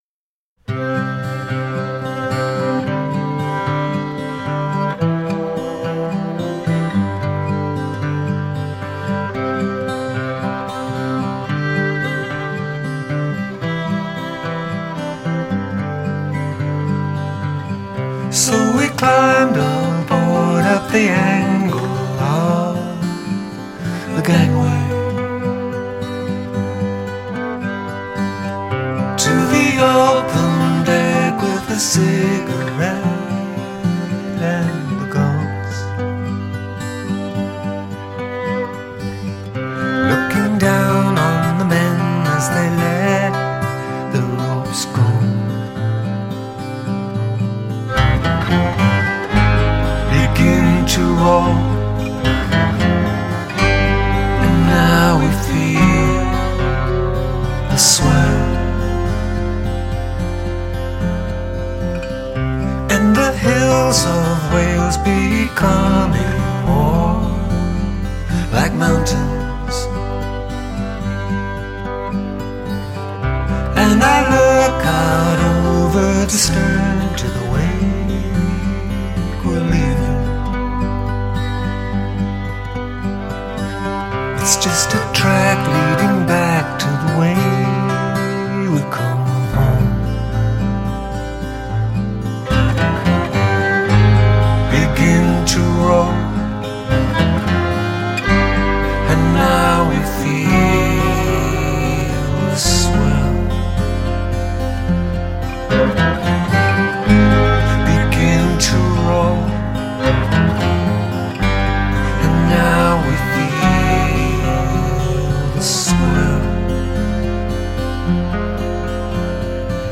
The warm, faintly antique-sounding folk sound